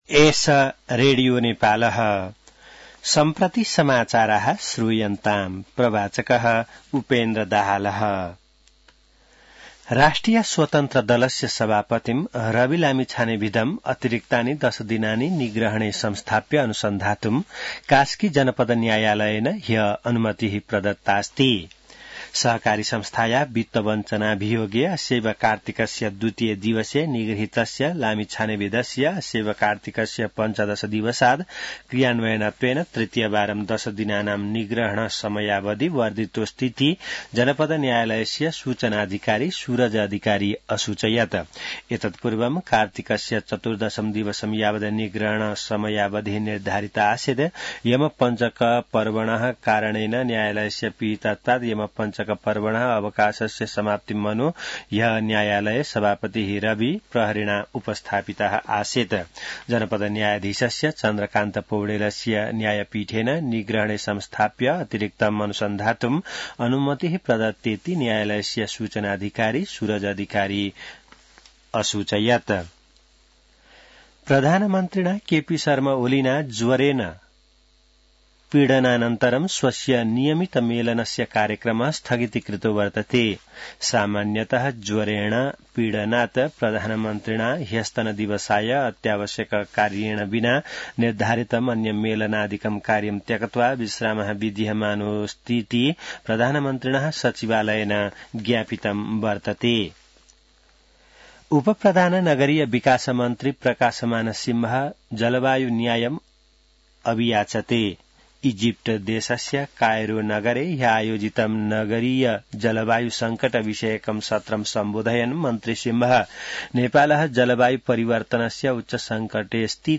संस्कृत समाचार : २२ कार्तिक , २०८१